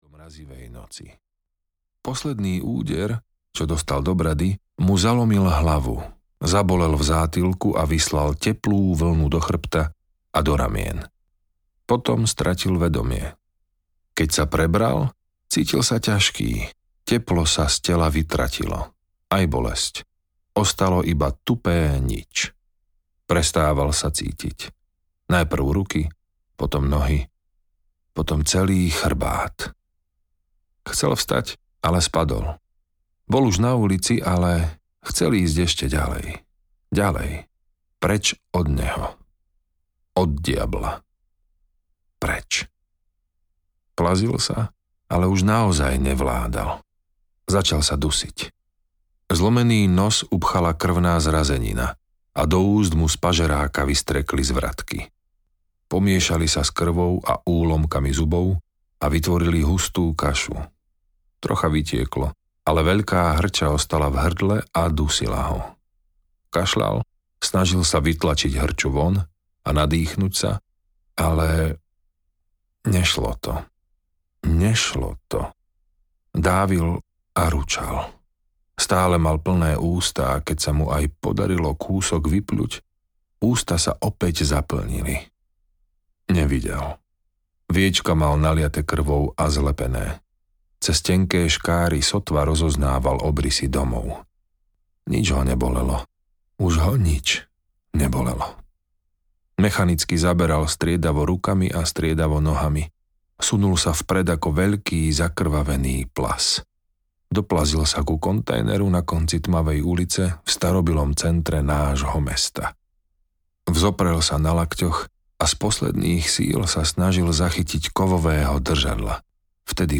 Hriech náš každodenný audiokniha
Ukázka z knihy